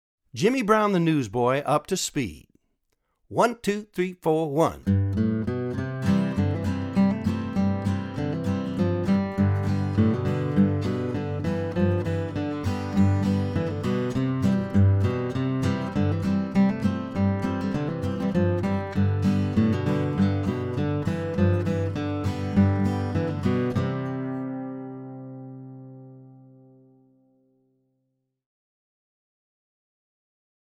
DIGITAL SHEET MUSIC - FLATPICK GUITAR SOLO
(both slow and regular speed)